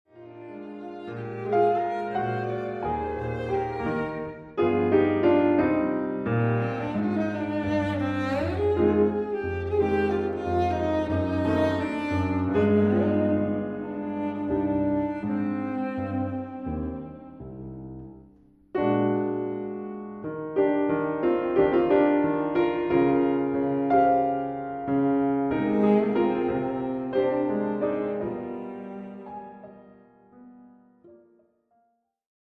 piano
Classical, Cross-cultural